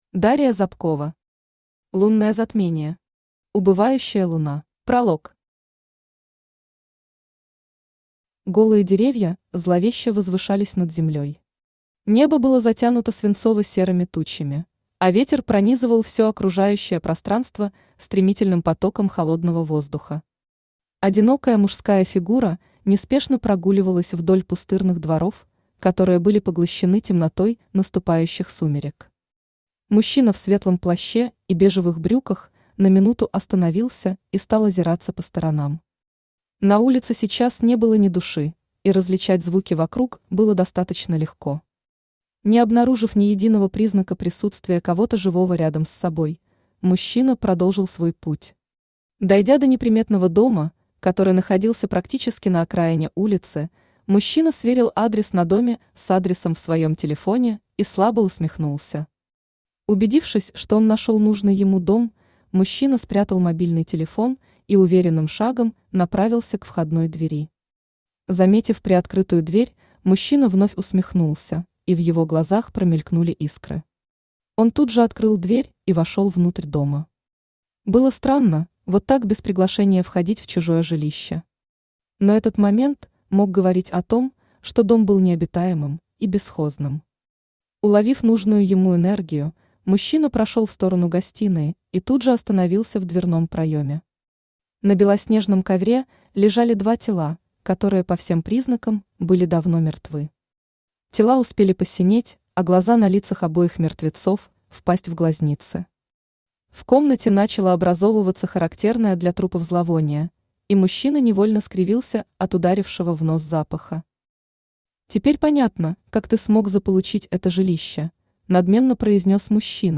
Аудиокнига Лунное Затмение. Убывающая луна | Библиотека аудиокниг